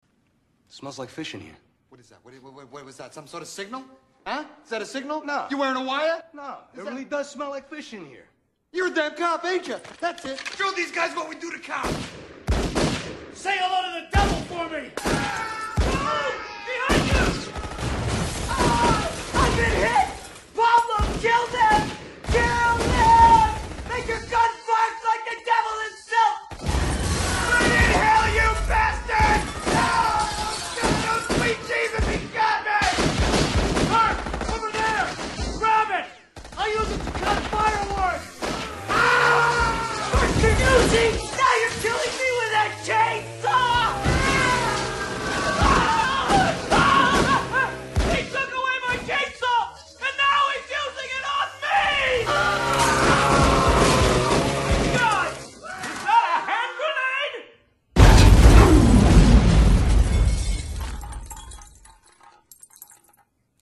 Category: Radio   Right: Personal
Tags: radio ruffian comedy